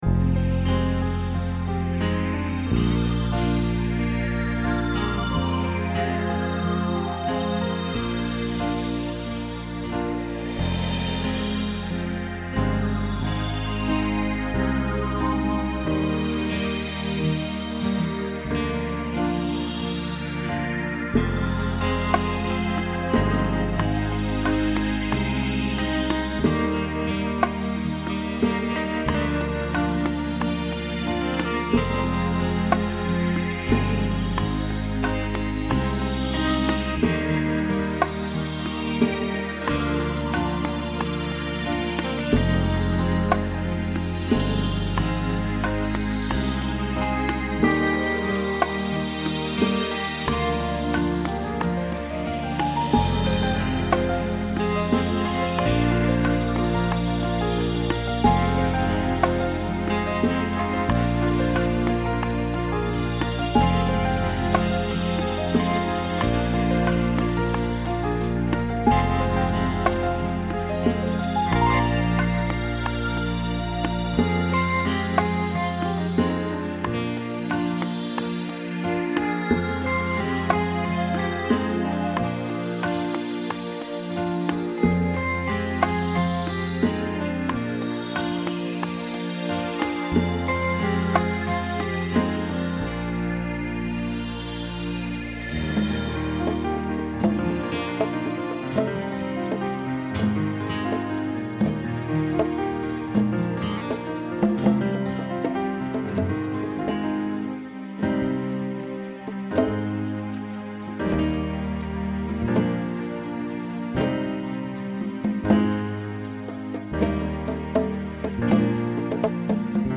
TTB/SSA
Voicing Mixed Instrumental combo Genre Pop/Dance
Mid-tempo